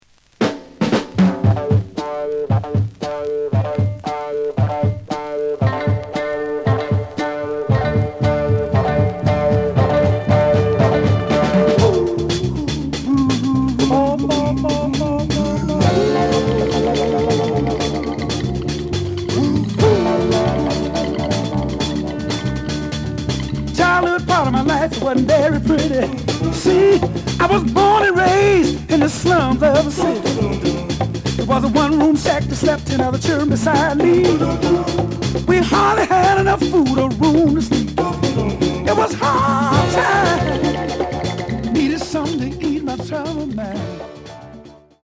The audio sample is recorded from the actual item.
●Genre: Soul, 60's Soul
Slight edge warp.